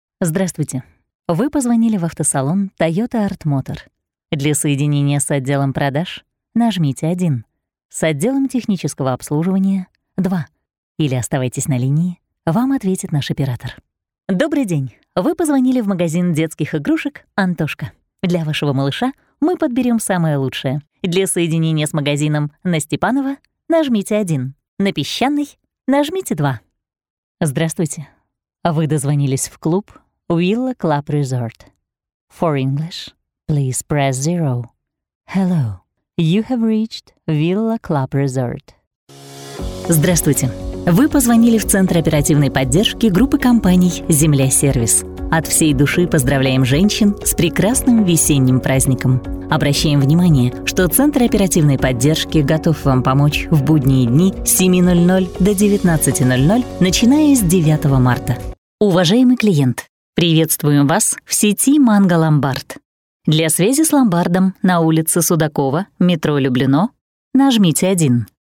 Тракт: Тракт записи голоса на сегодня такой: Микрофон Neumann TLM 103 PreSonus Revelator Запись и редактирование MacBook Pro Профессиональная акустическая кабина WhisperRoom
Демо-запись №1 Скачать